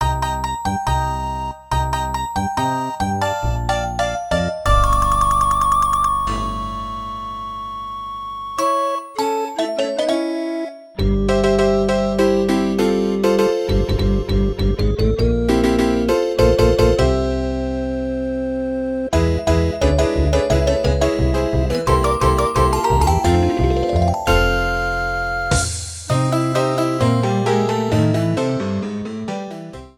The good ending theme
Converted from .mid to .ogg